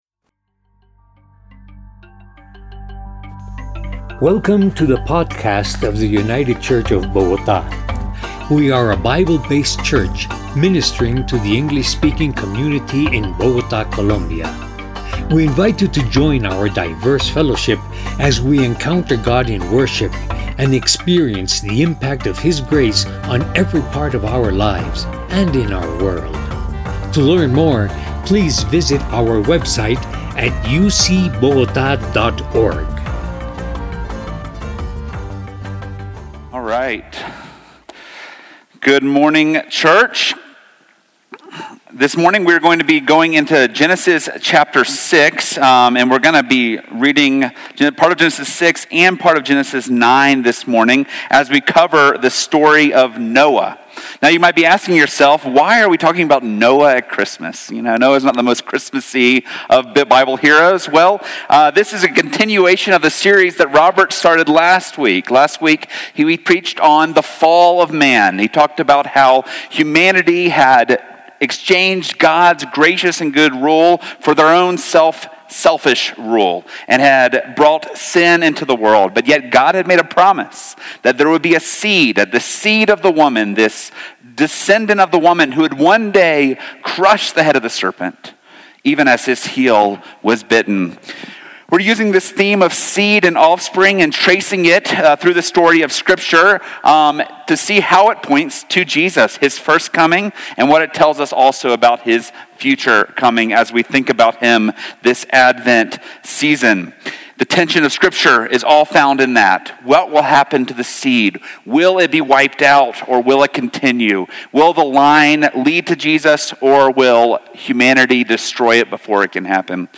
Categories: Sermons